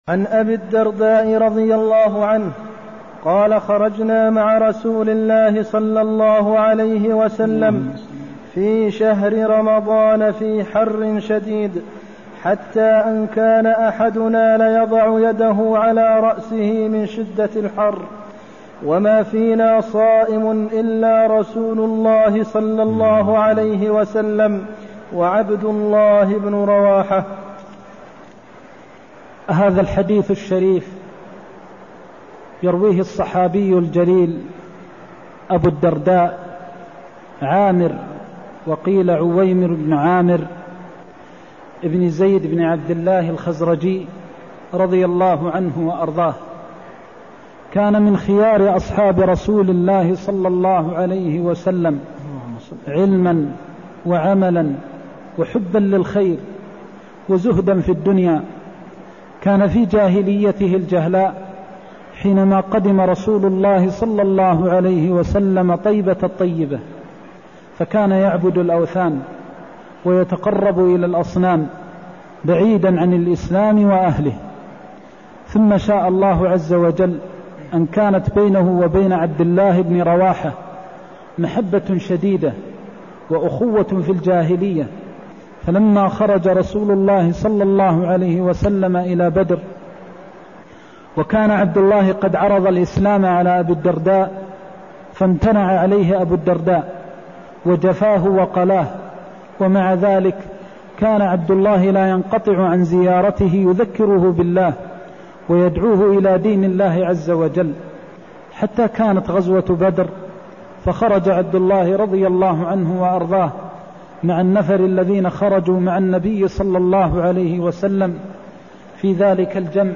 المكان: المسجد النبوي الشيخ: فضيلة الشيخ د. محمد بن محمد المختار فضيلة الشيخ د. محمد بن محمد المختار التخيير في الصوم والفطر في السفر (179)